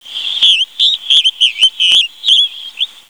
If your computer has a sound card you can click on this picture of a sonogram to hear the bird song